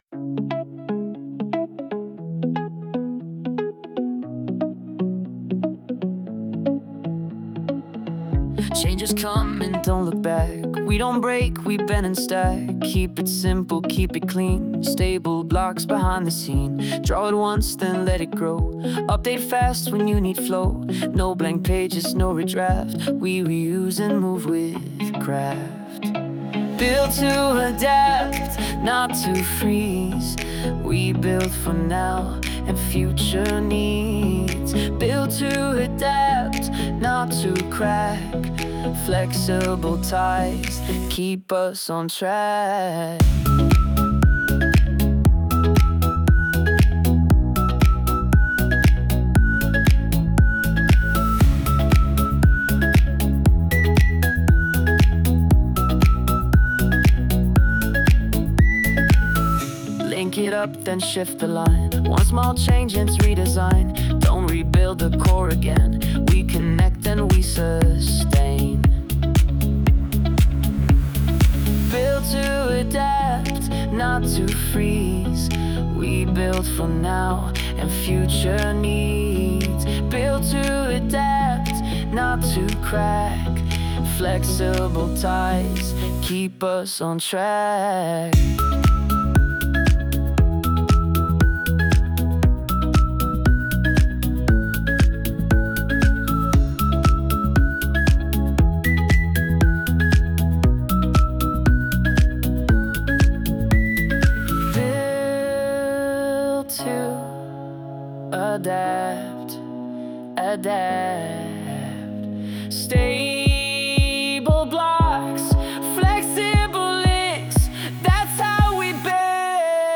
Tropical House · 117 BPM · Eng